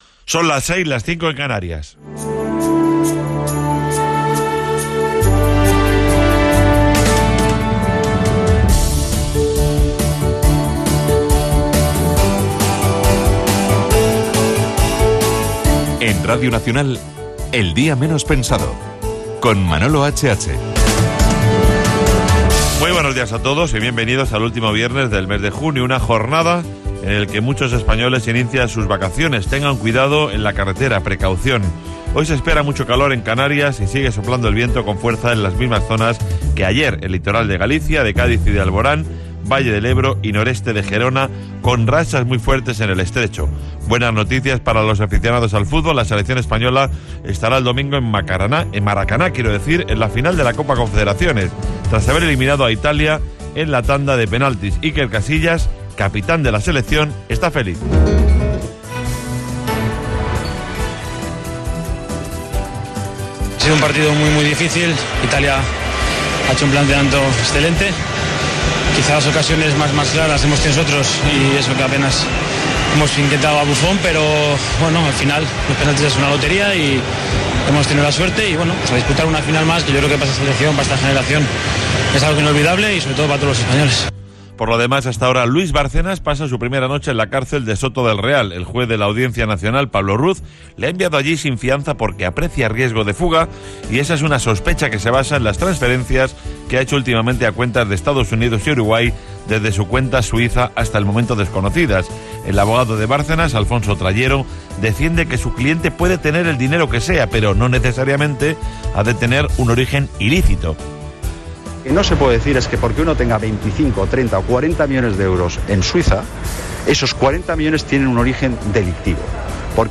Careta, presentació, informació destacada (amb declaració del jugador Íker Casillas i del polític Alberto Ruiz-Gallardón), resum informatiu, indicatiu, hora. Luis Bárcenas, tresorer del Partido Popular, empresonat, cimera europea i fons europeus
Info-entreteniment